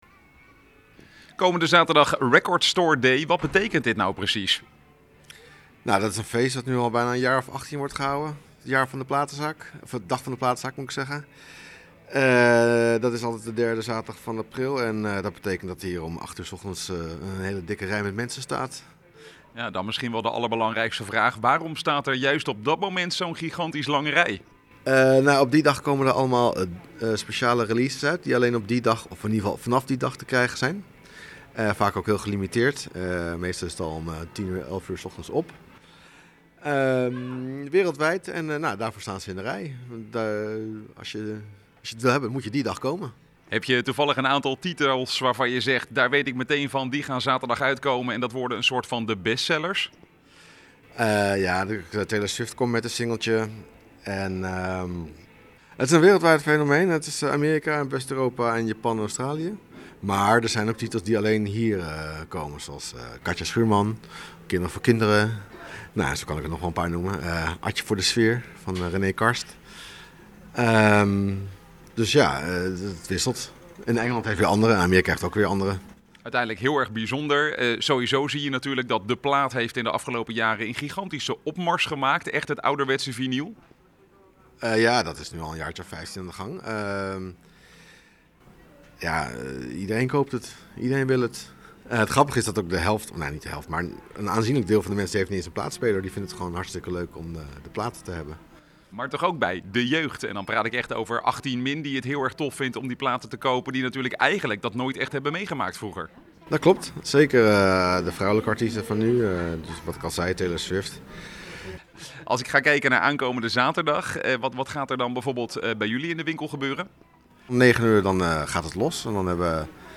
in gesprek met platenzaak eigenaar